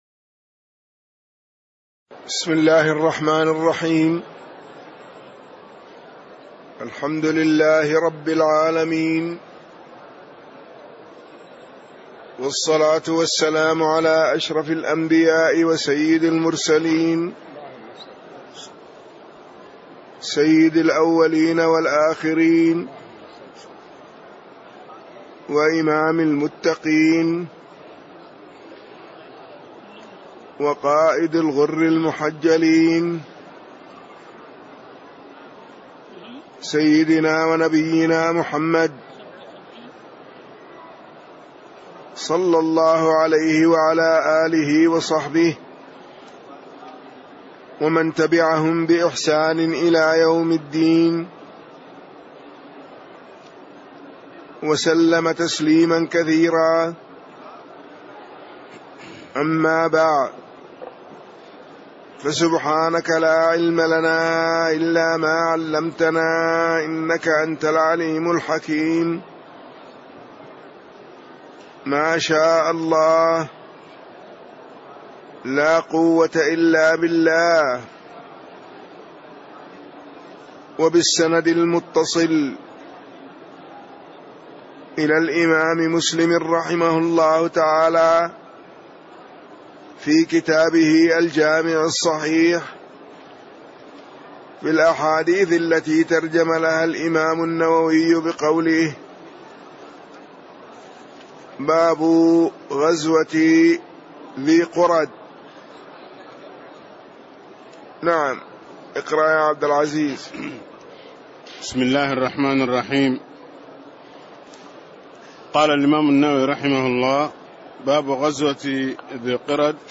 تاريخ النشر ٢٤ محرم ١٤٣٦ هـ المكان: المسجد النبوي الشيخ